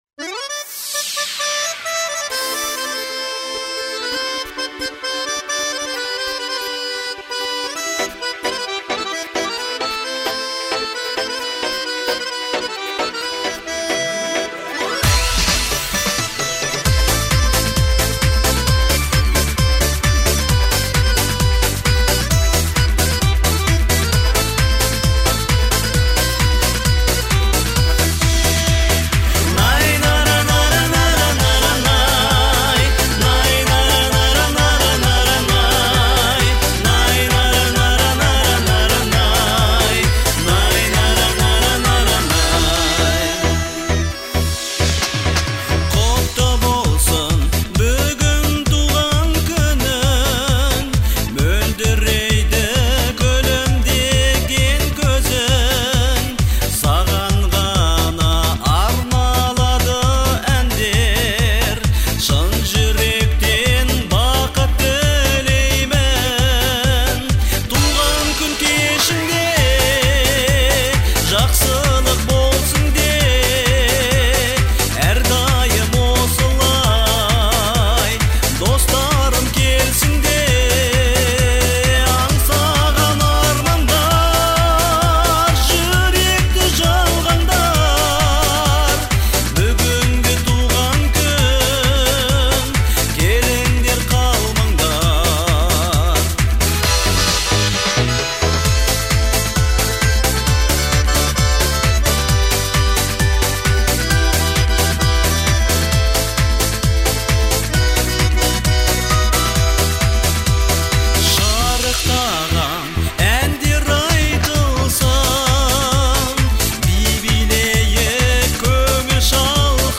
это яркая и эмоциональная песня в жанре поп